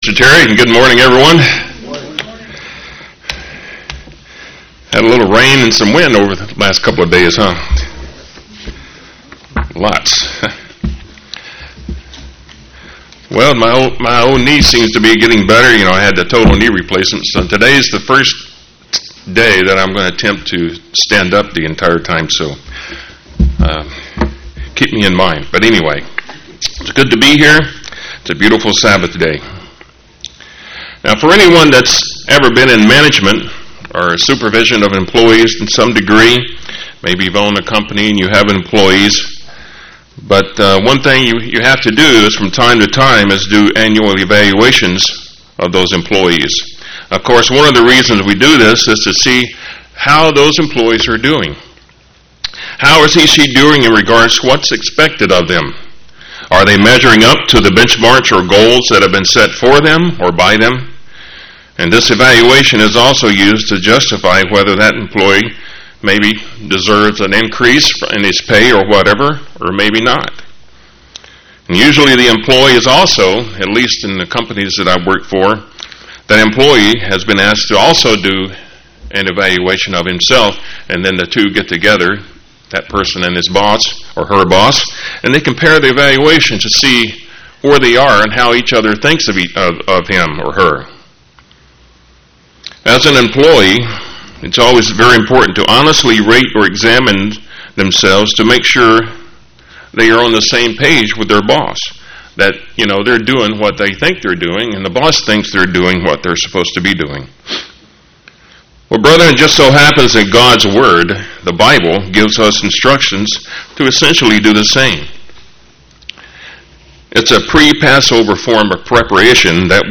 Given in St. Petersburg, FL
UCG Sermon Studying the bible?